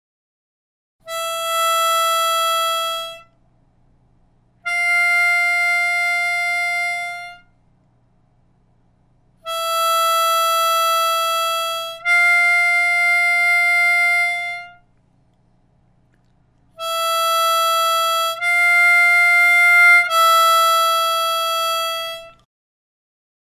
Primeros ejercicios básicos para práctica de notas simples
Ejercicios-Celda-5-sin-movimiento.mp3